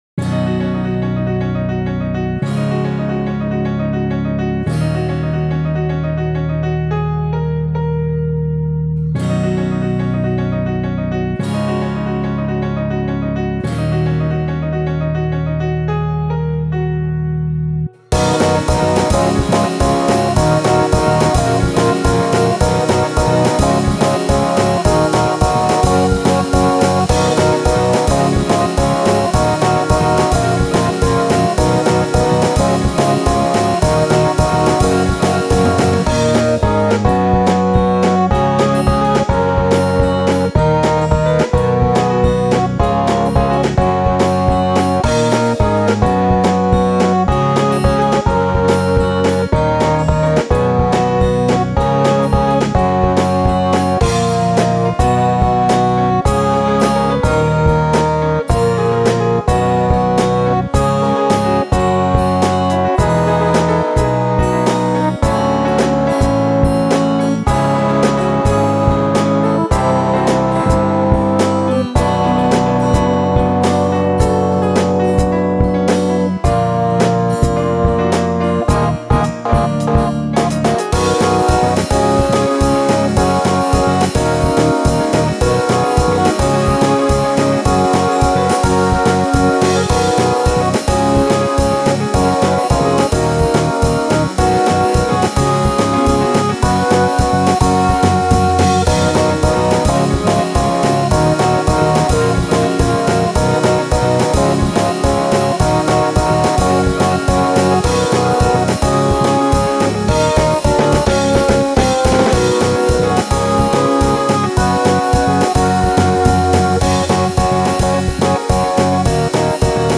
なにか始まりそう。Bメロの途中で転調かますの割と攻めた感じがする。